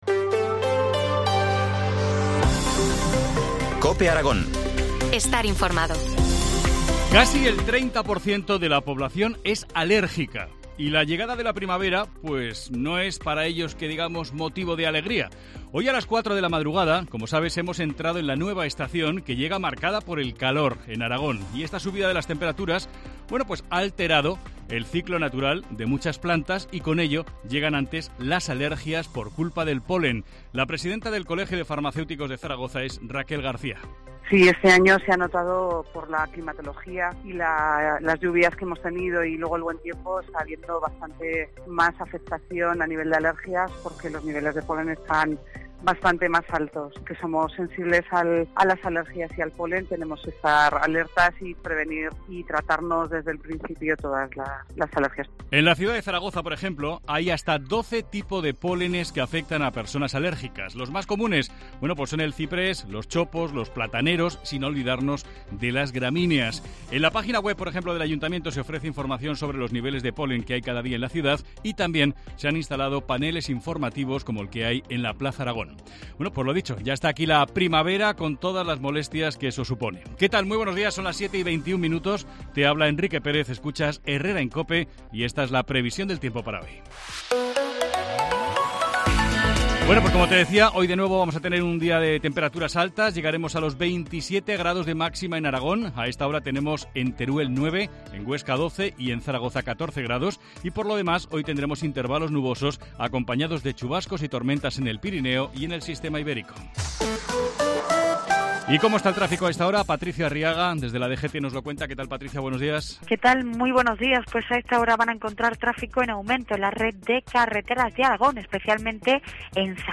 Titulares del día en COPE Aragón